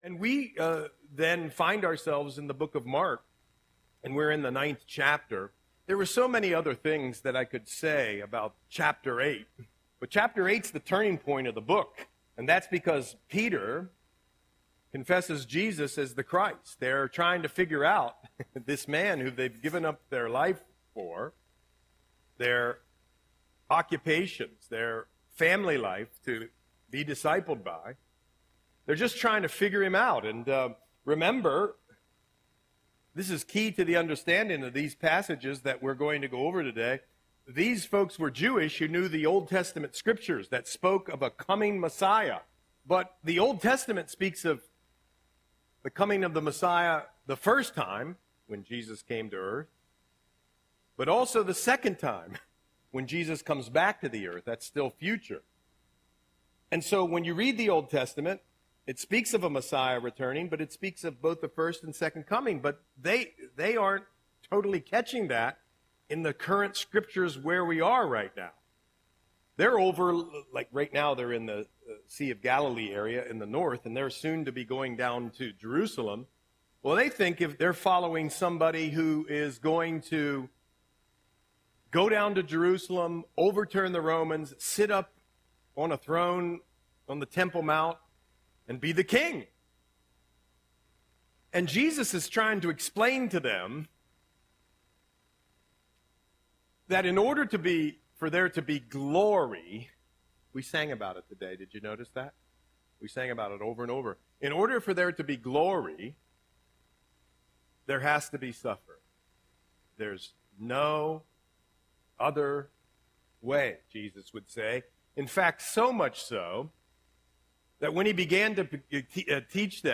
Audio Sermon - January 19, 2025